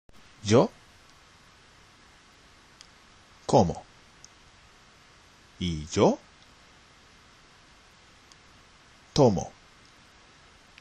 ・CDからは、まず一日の流れに沿って動詞を1人称活用するフレーズが流れます。
（全てスペイン語です。日本語は入っていません）
サンプル音声の「Yo・・」の後の空白時間（約3秒）の間に下のカードで自分で活用してみましょう。